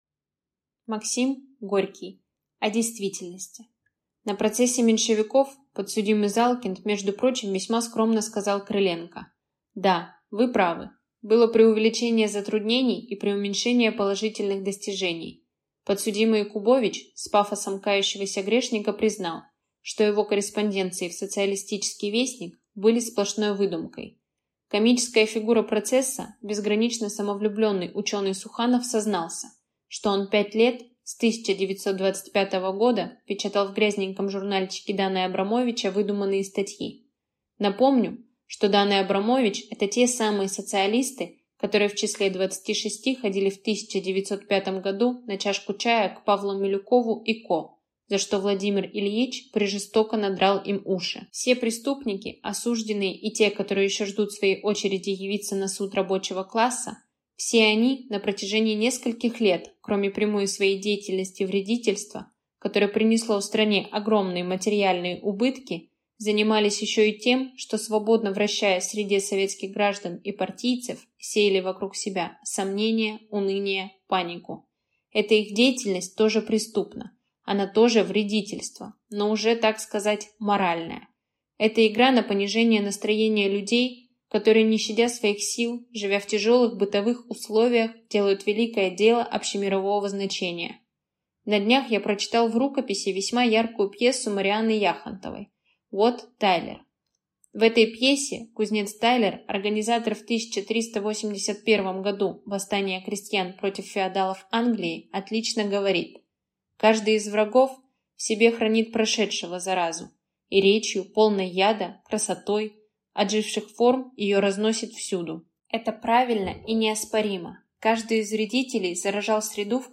Аудиокнига О действительности | Библиотека аудиокниг